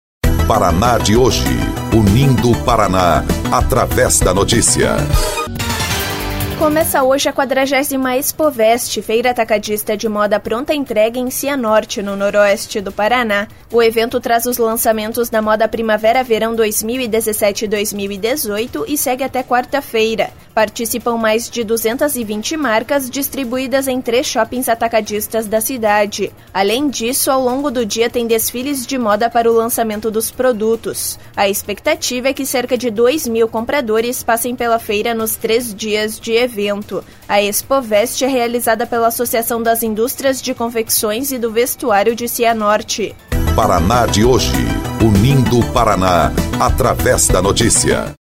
BOLETIM – Expovest pretende reunir cerca de dois mil compradores em Cianorte